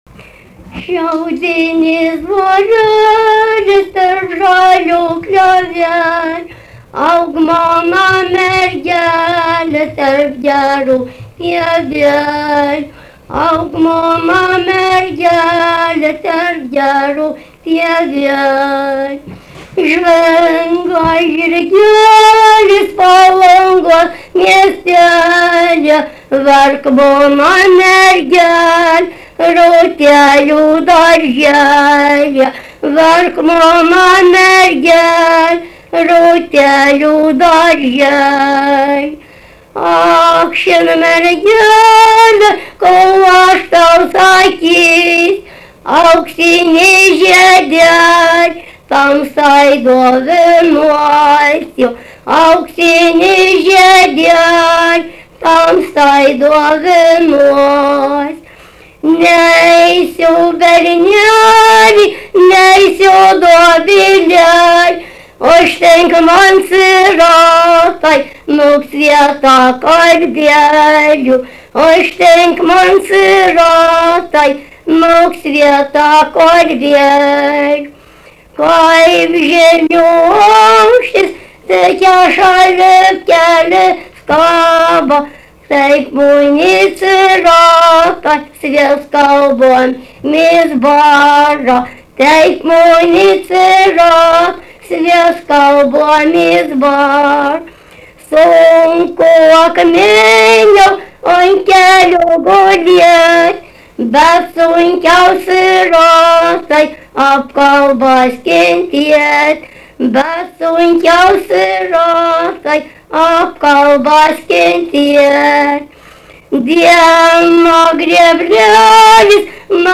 daina
Daukšiai (Skuodas)
vokalinis